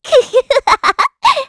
Estelle-Vox_Happy4_kr.wav